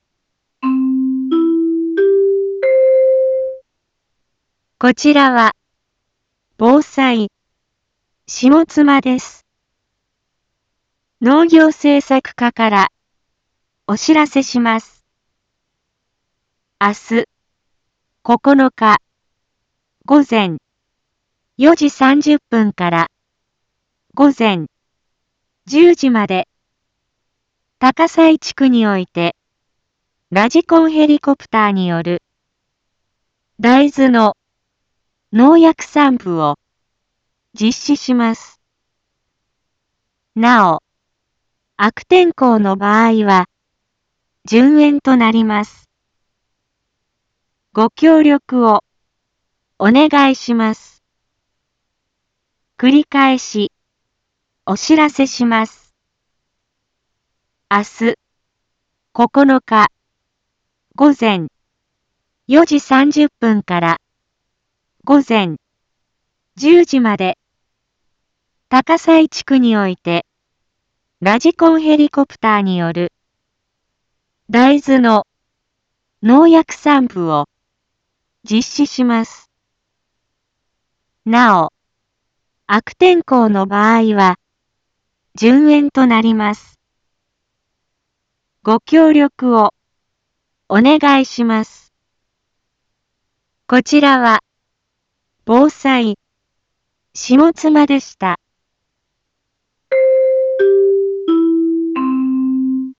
一般放送情報
Back Home 一般放送情報 音声放送 再生 一般放送情報 登録日時：2025-09-08 12:32:00 タイトル：大豆の無人ヘリによる空中防除 インフォメーション：こちらは、ぼうさいしもつまです。